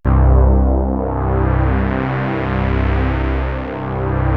JUP.8 C3   2.wav